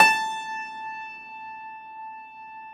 53a-pno17-A3.wav